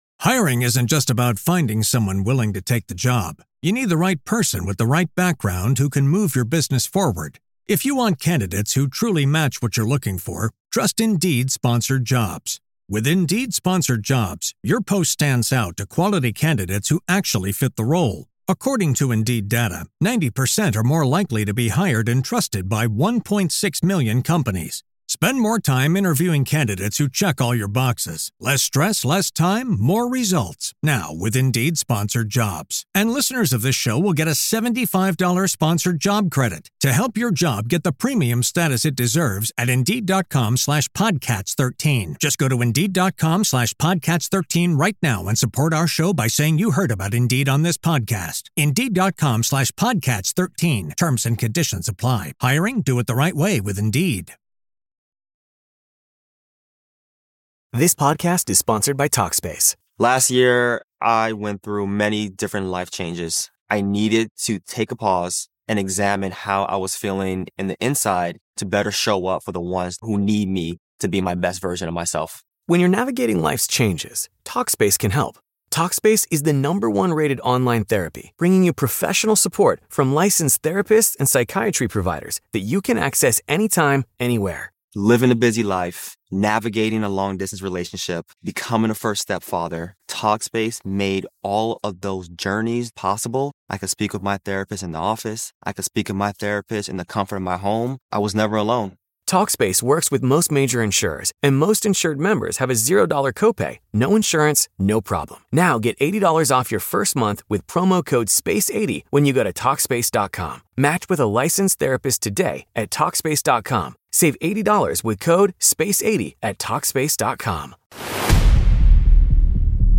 Beschreibung vor 4 Monaten In dieser On-the-Road-Folge (auf dem Weg nach Dresden) wird’s persönlich – und gleichzeitig ziemlich praktisch für alle, die IT verantworten: Warum ist der Dezember für viele CIOs der Monat der Reflexion, Budget-Feinschliffe und Audit-Vorbereitungen? Was war planbar, was kam ungeplant – und wie behält man trotz Run- und Build-Budget die Kontrolle?